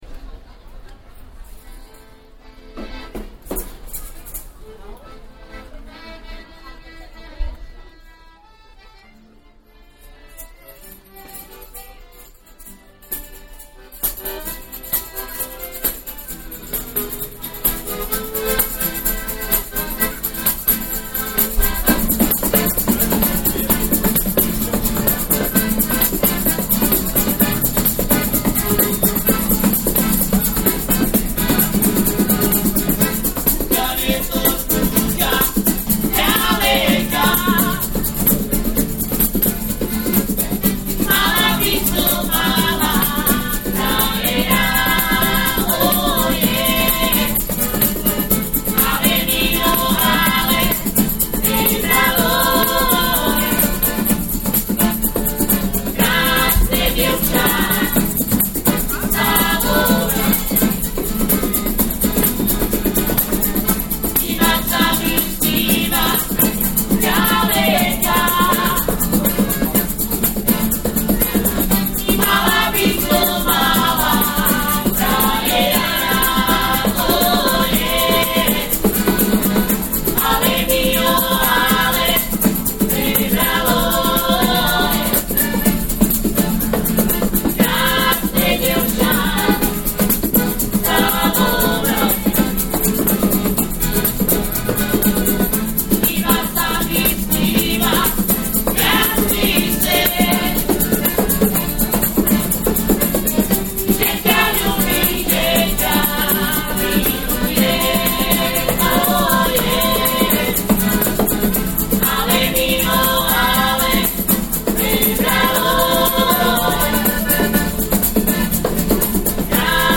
Awesome buskers from Bern last year (30/10/09).